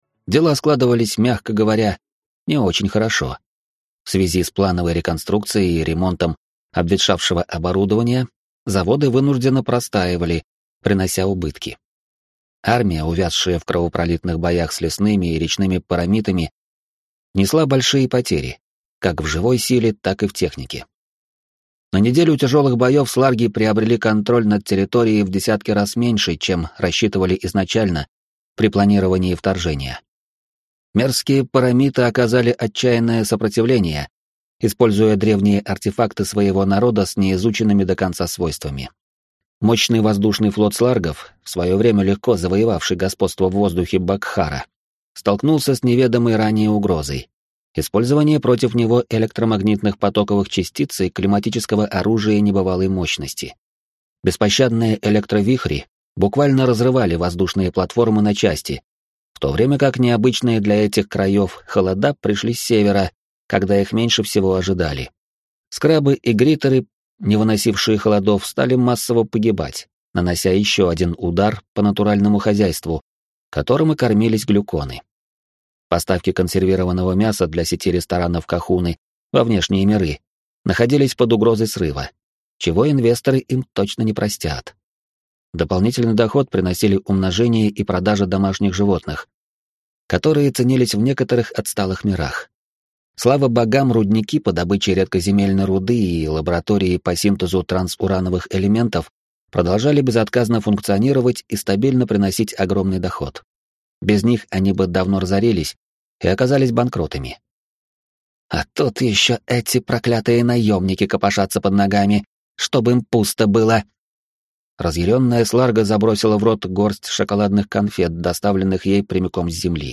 Аудиокнига Потерянный рай. Том 2 | Библиотека аудиокниг